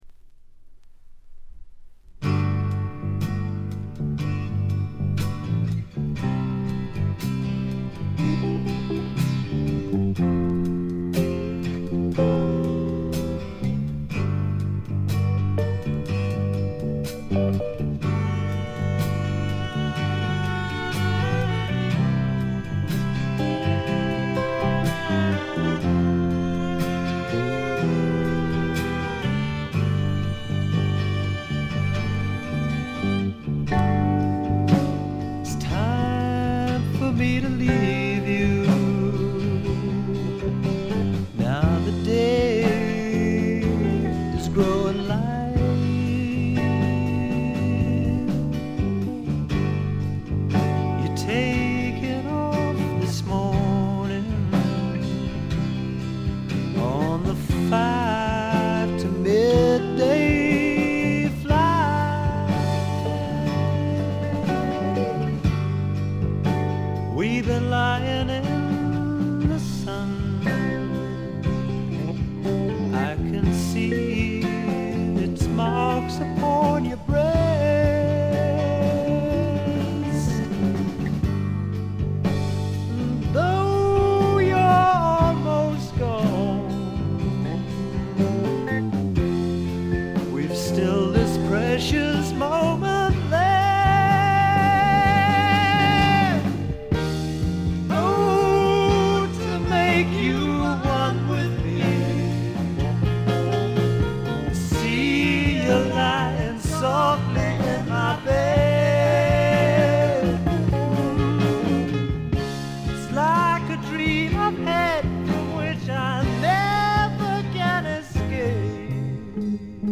ほとんどノイズ感無し。
内容は枯れた感じのフォーク・ロック基調でちょっと英国スワンプ的な雰囲気もあり、実に味わい深いアルバムとなりました。
試聴曲は現品からの取り込み音源です。
Guitar, Piano, Violin, Vocals
Drums, Percussion